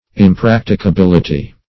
Impracticability \Im*prac`ti*ca*bil"i*ty\, n.; pl.